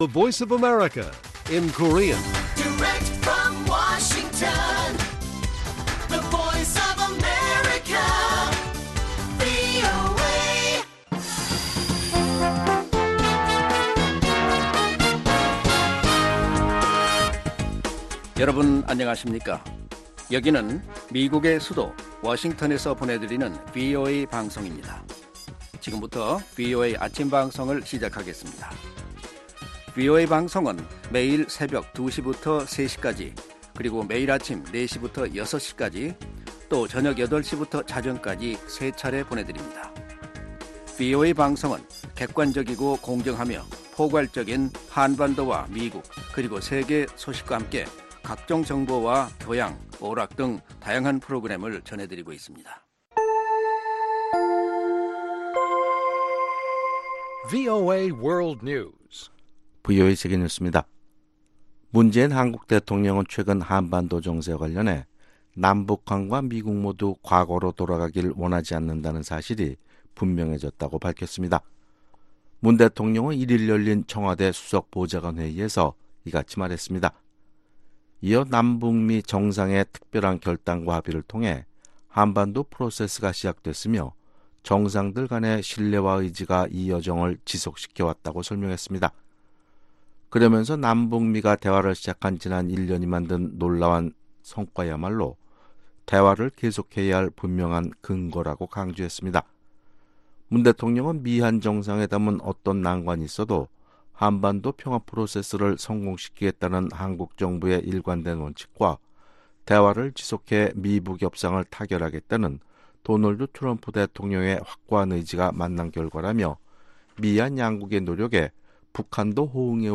세계 뉴스와 함께 미국의 모든 것을 소개하는 '생방송 여기는 워싱턴입니다', 2019년 4월 2일 아침 방송입니다.